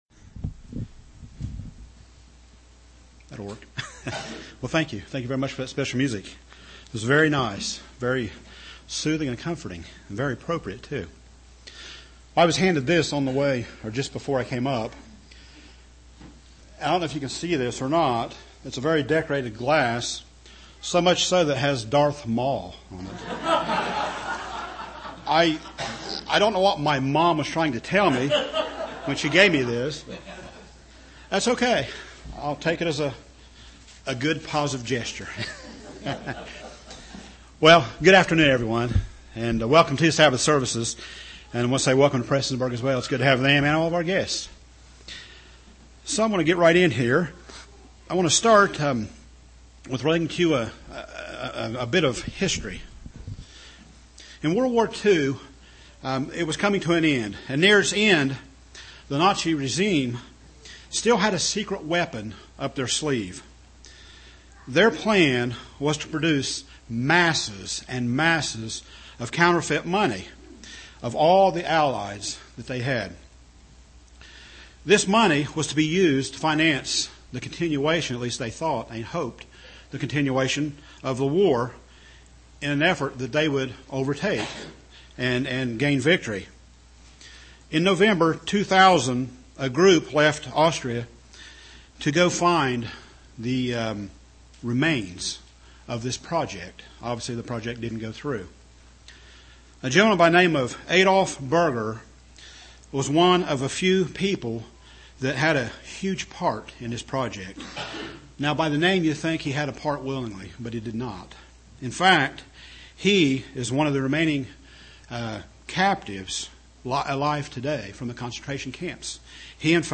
Given in Portsmouth, OH
UCG Sermon Studying the bible?